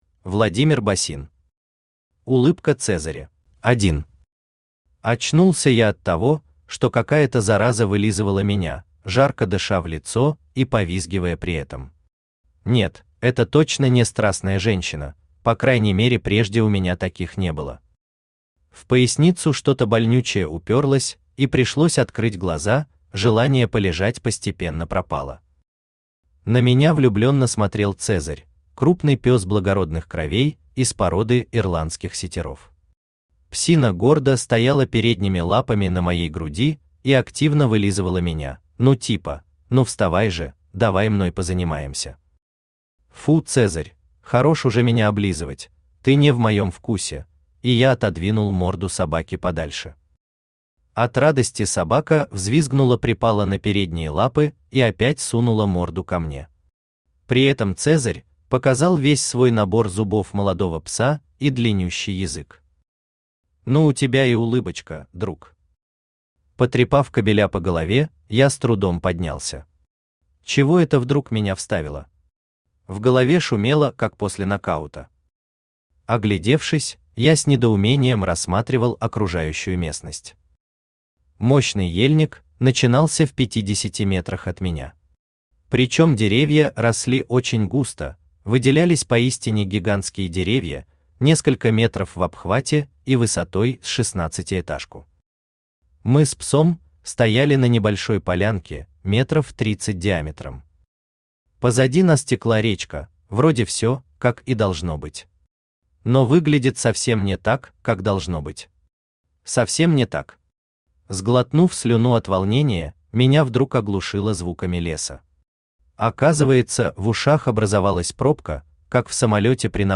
Аудиокнига Улыбка Цезаря | Библиотека аудиокниг
Aудиокнига Улыбка Цезаря Автор Владимир Георгиевич Босин Читает аудиокнигу Авточтец ЛитРес.